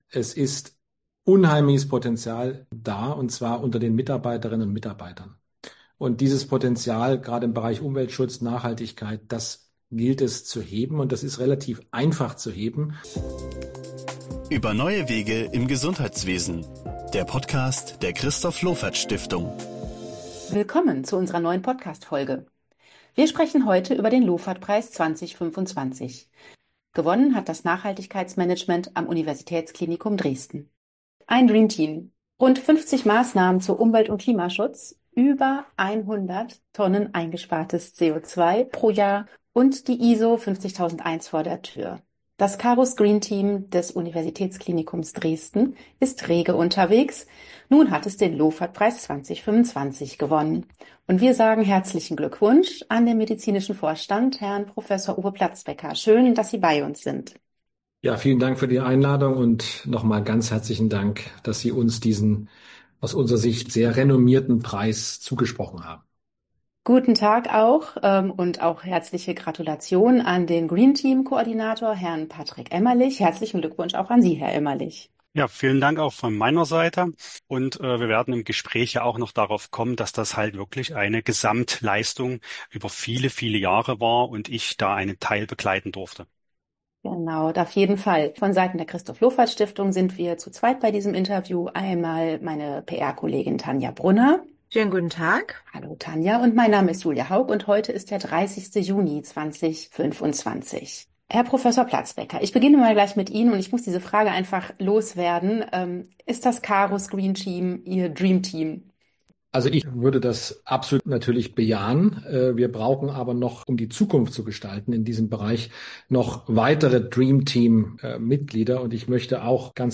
Das Interview im Überblick: 1:49 – Ist das Carus Green Team Ihr Dreamteam?